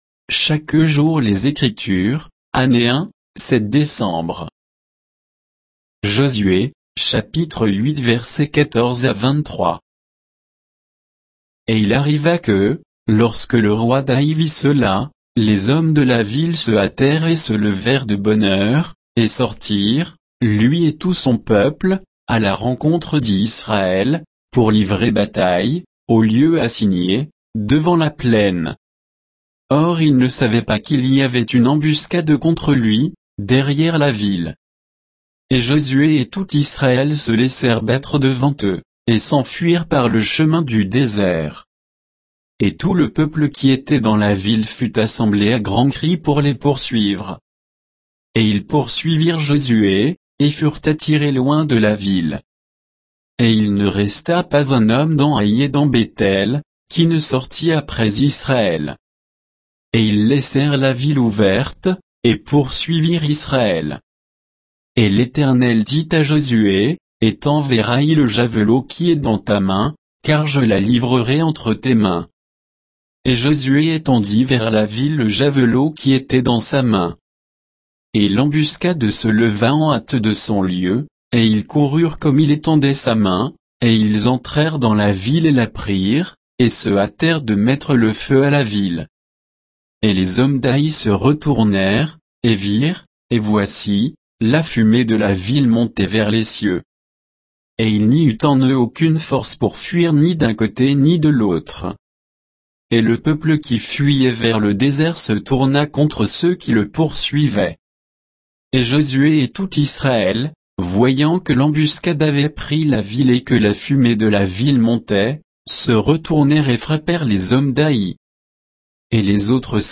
Méditation quoditienne de Chaque jour les Écritures sur Josué 8